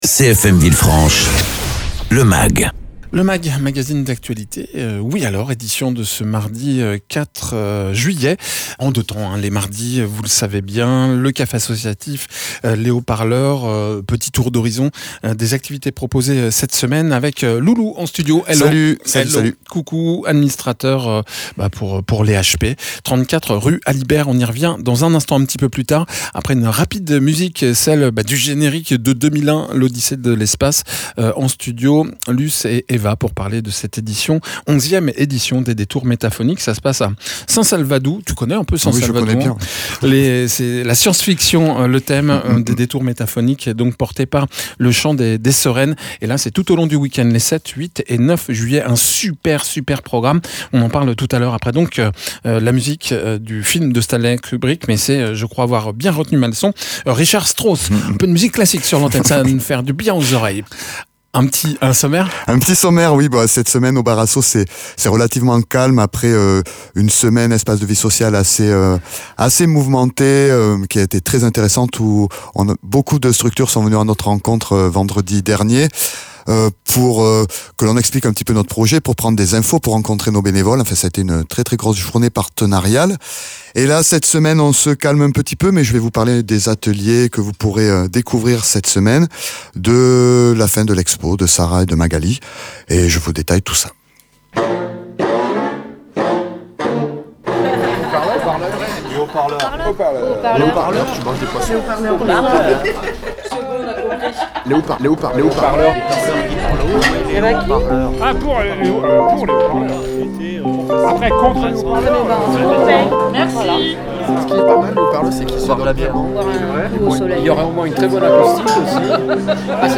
Mags